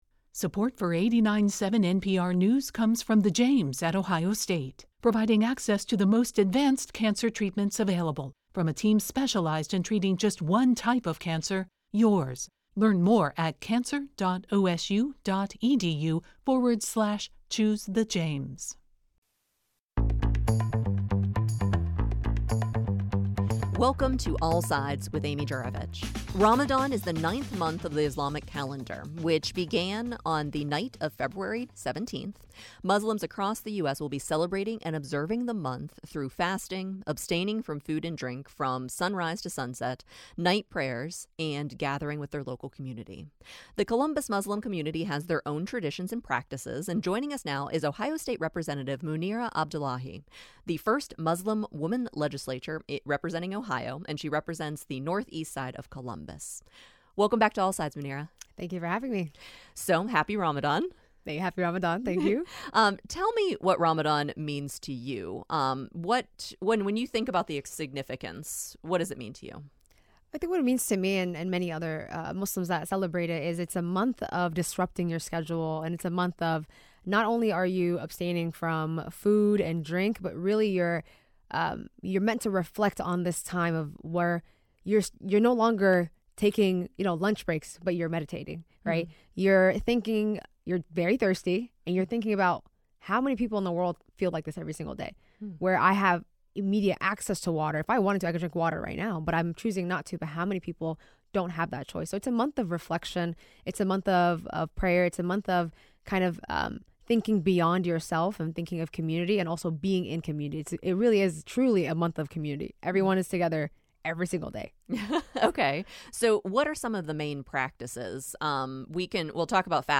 We’ll be speaking with three local Muslim community leaders about what it’s like to celebrate Ramadan in Columbus.